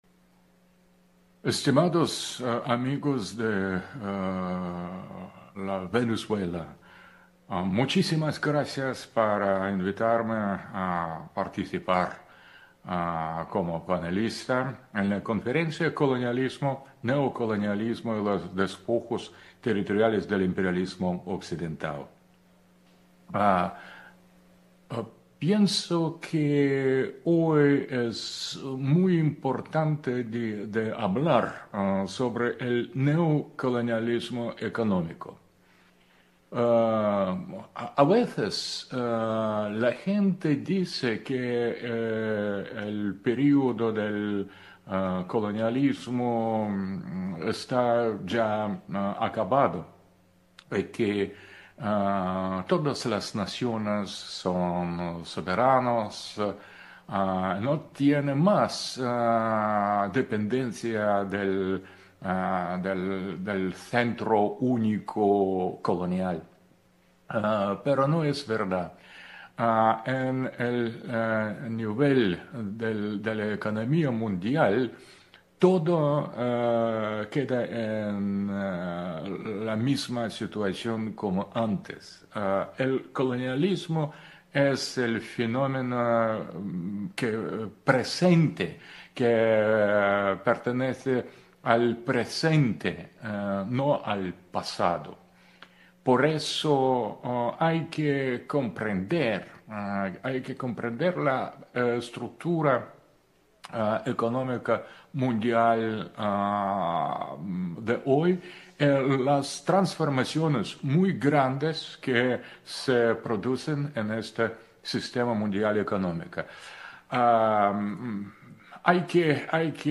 Foro Colonialismo, Neocolonialismo Caracas 2.3 octubre 2025